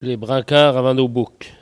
Elle provient de Saint-Jean-de-Monts.
Catégorie Locution ( parler, expression, langue,... )